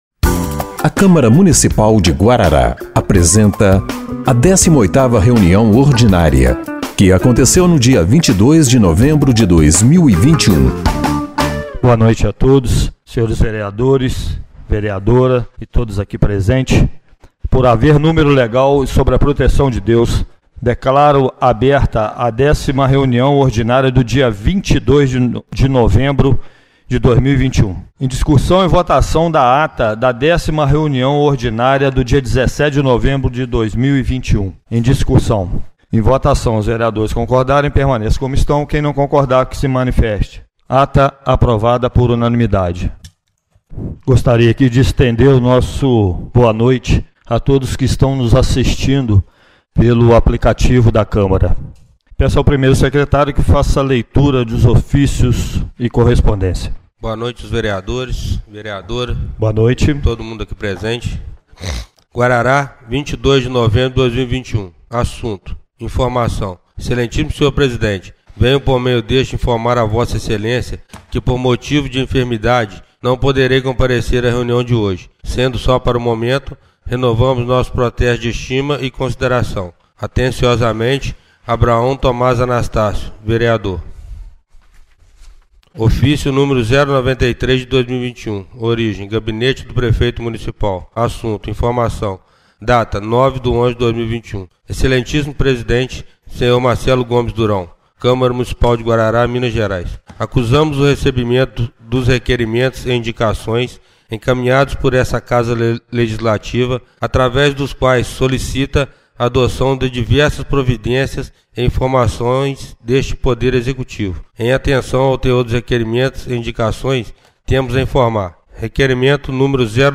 18ª Reunião Ordinária de 22/11/2021